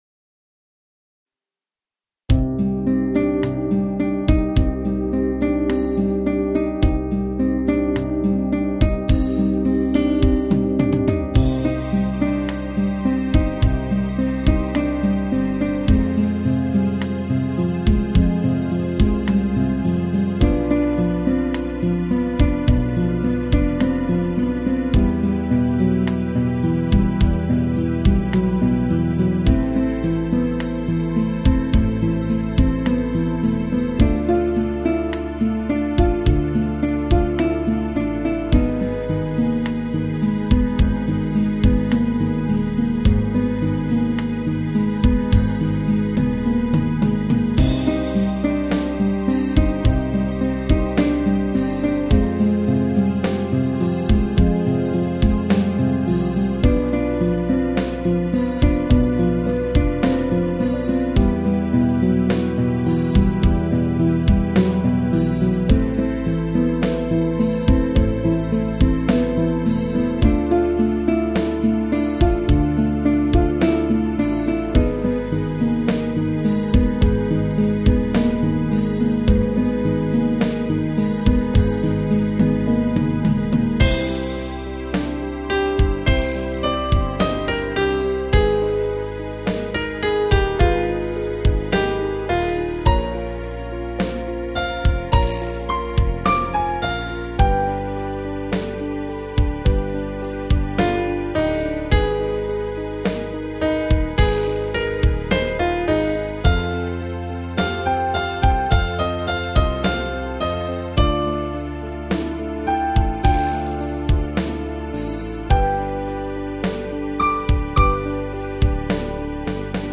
BALLADS